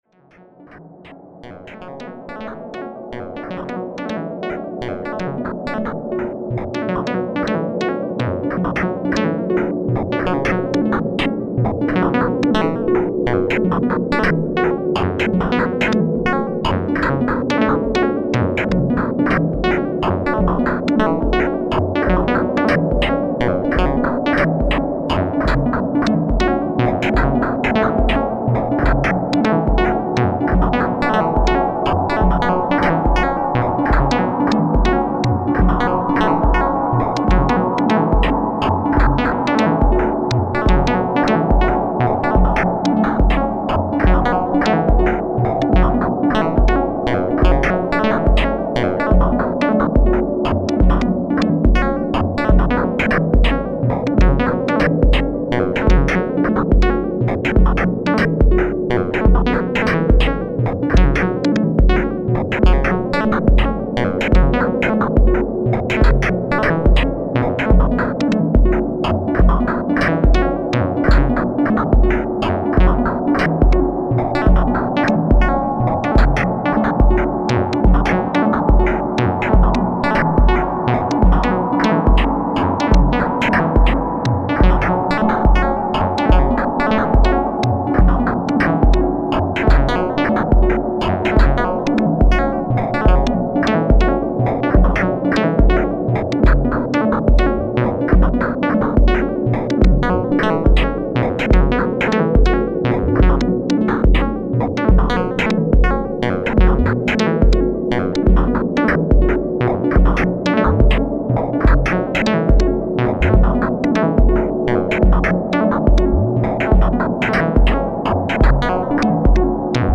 ambient syntaktech
Now i know why the analog filter on the fx track makes it special.